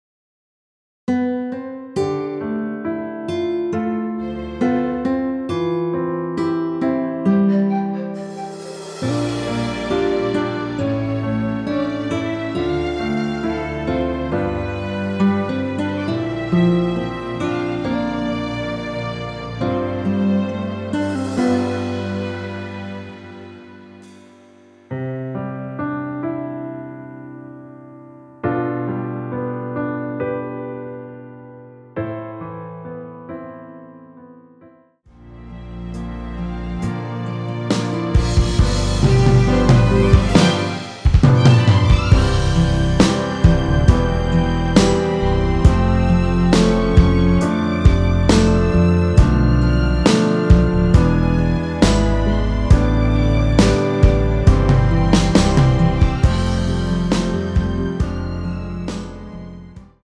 남성분이 부르실 수 있는 키의 MR입니다.
원키에서(-5)내린MR입니다.
앞부분30초, 뒷부분30초씩 편집해서 올려 드리고 있습니다.